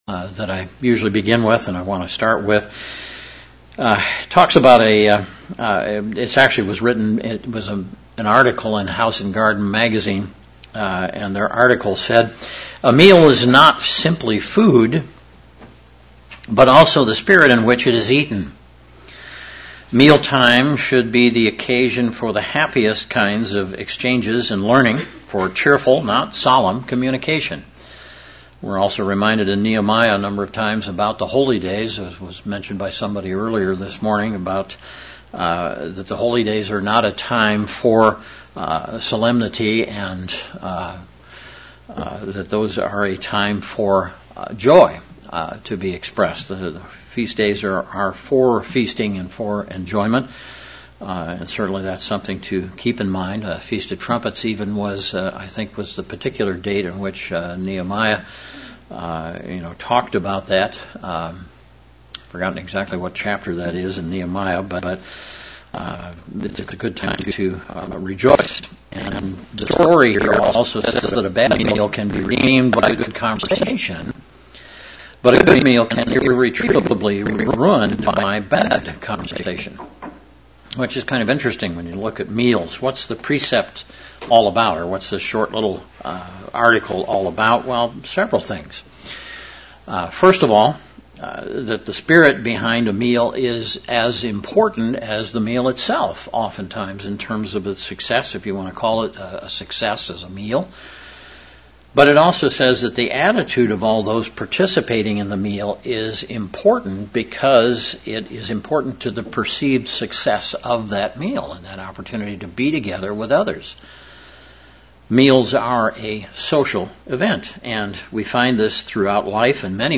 Given in Central Illinois
:) UCG Sermon Studying the bible?